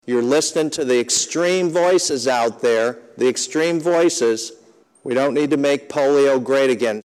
Senator Joe Bolkcom of Iowa City says there’s a movement to undermine vaccinations.